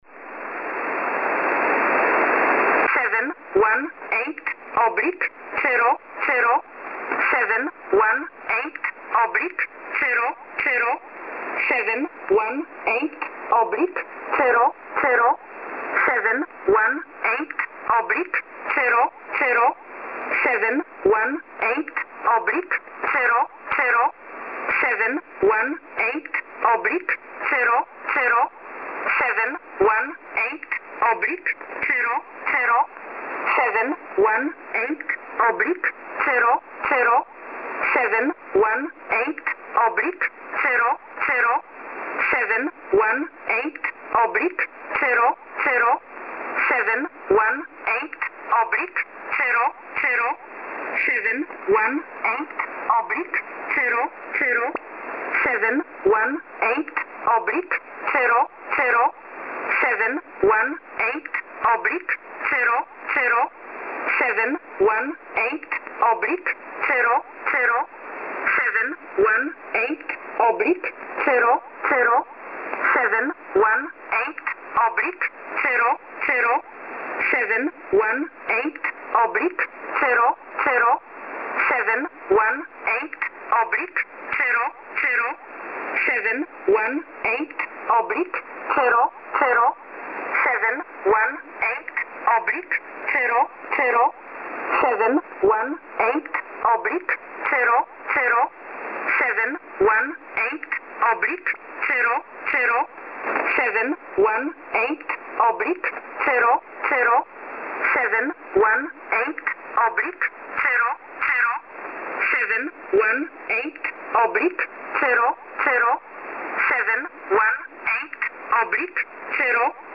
E11a Nunber Station (15915kHz) recorded 2014-10-23, 12:00UTC